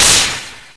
se_slash.ogg